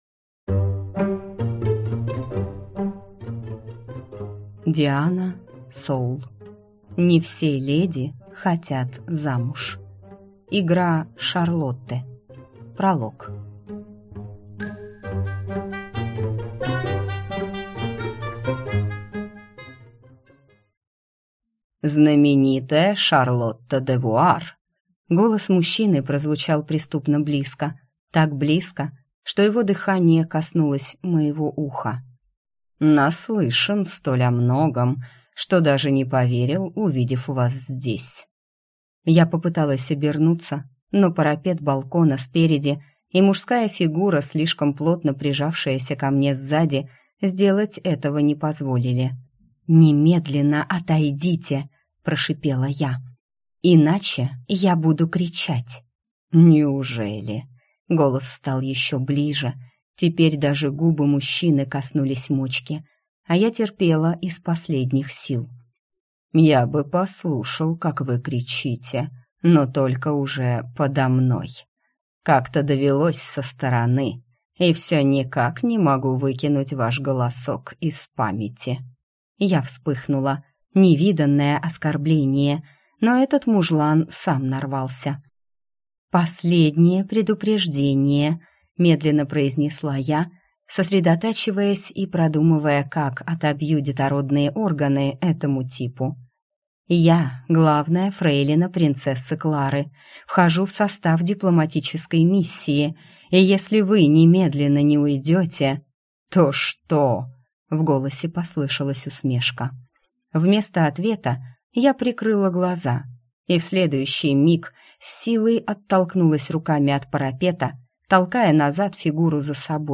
Аудиокнига Не все леди хотят замуж. Игра Шарлотты | Библиотека аудиокниг